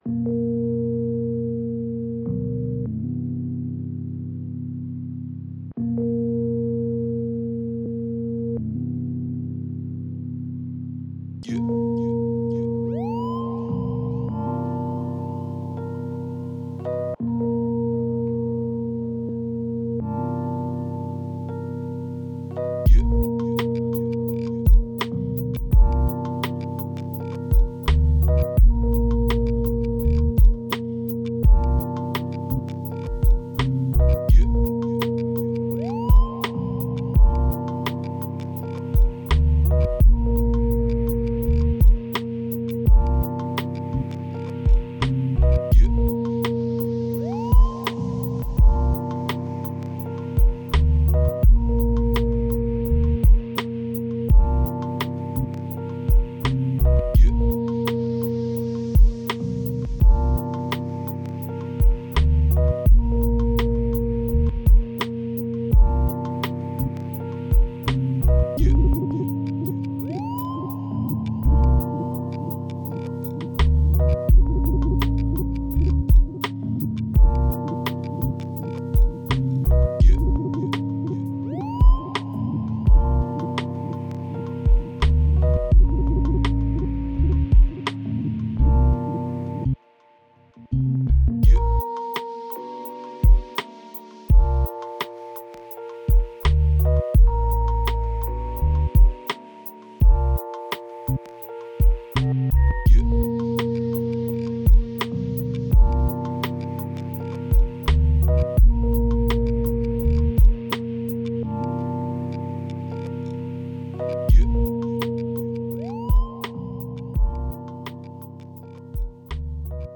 A different vibe, darker. My latest beat with the M8.